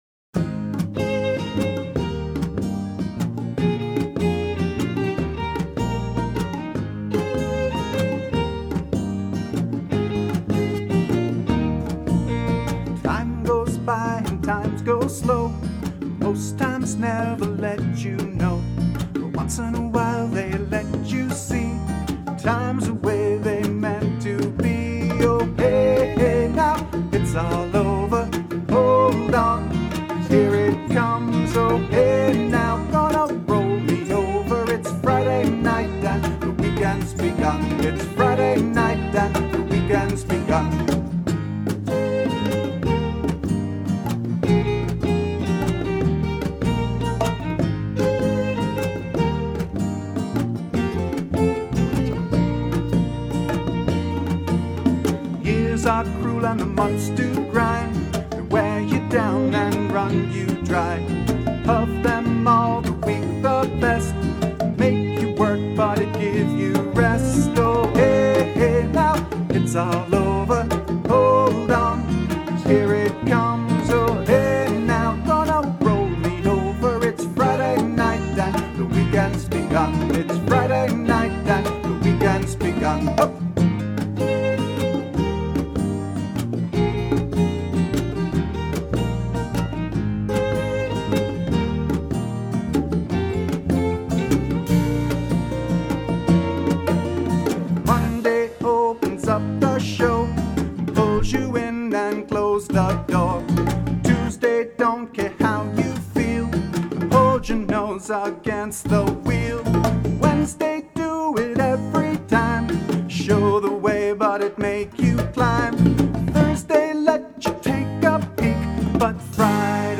Accompanying my acoustic guitar and vocals are
bass guitar
percussion
violin.